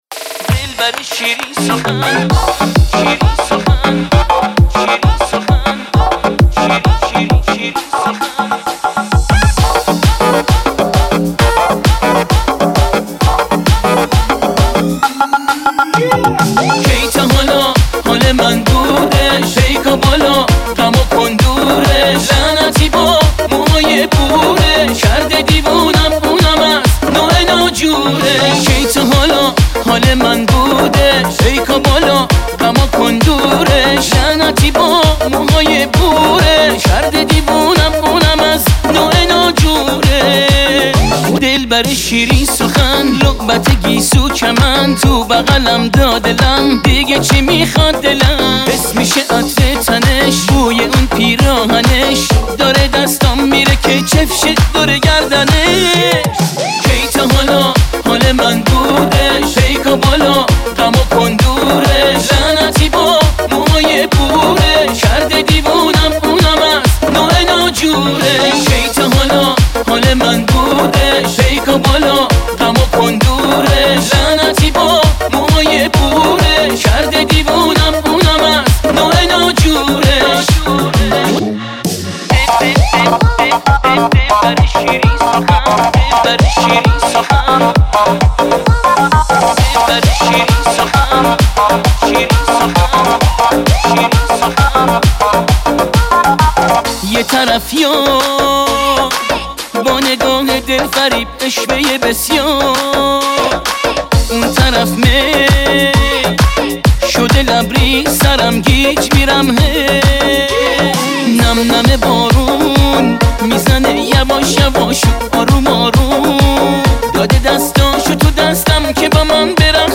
آهنگ جدید و شاد ۹۸